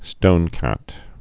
(stōnkăt)